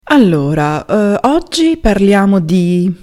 Listen to a teacher who is speaking in class: